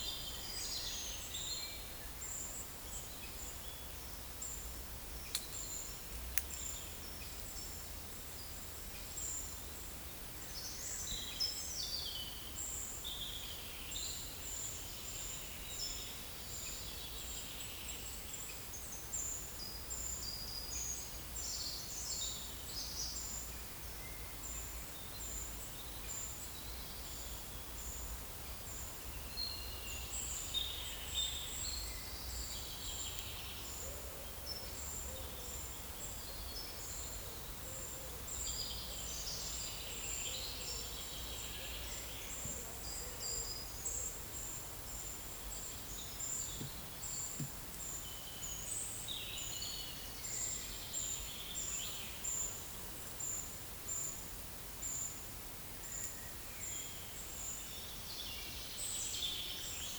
Certhia brachydactyla
Certhia familiaris
Erithacus rubecula